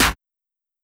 Claps